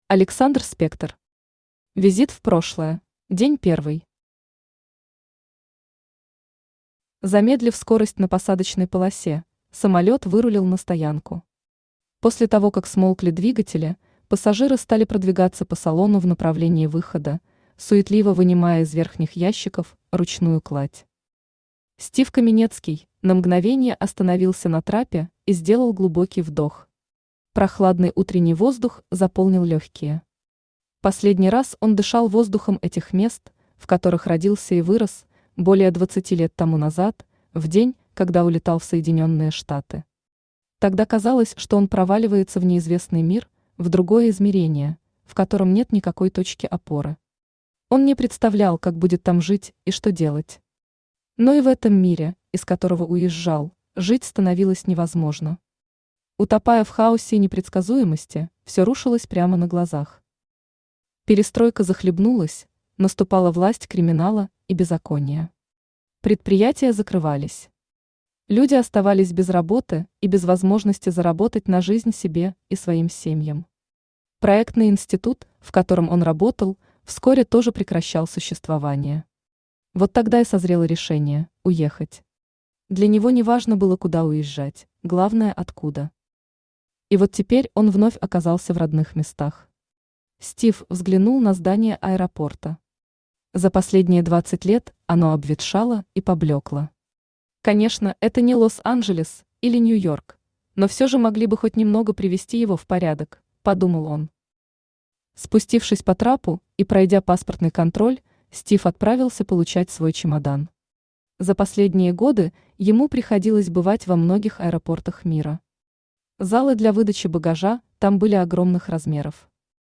Aудиокнига Визит в прошлое Автор Александр Спектор Читает аудиокнигу Авточтец ЛитРес.